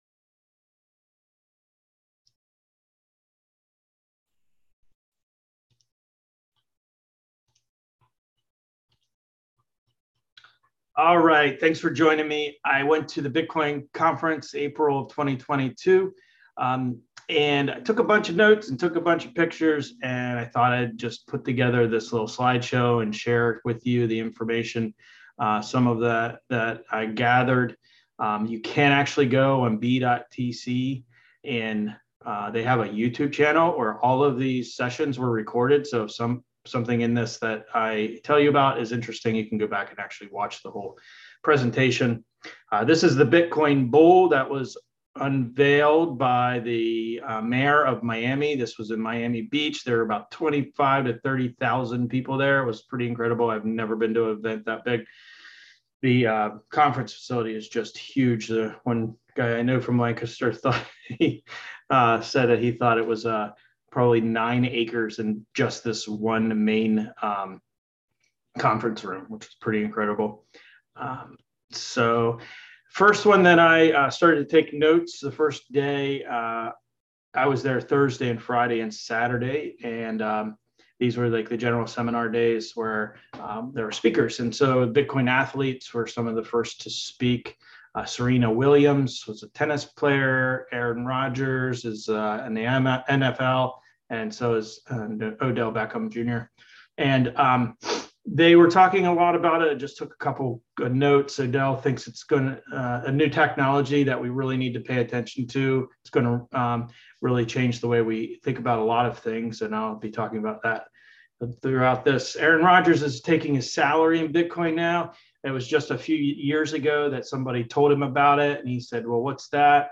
This is a video of me reviewing my notes and talking about the Bitcoin 2022 Conference. It was a great time with a ton of great information about how Bitcoin is going to revolutionize how finance works and how it will change entire civilizations.